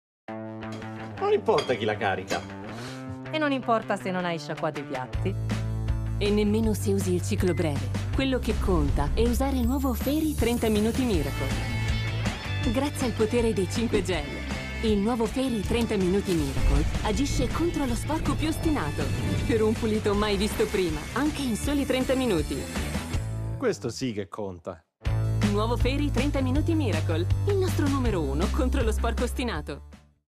Female
Soft voice, warm, intense, suitable for institutional intonations, but also smiling and solar, dynamic and sparkling.
Television Spots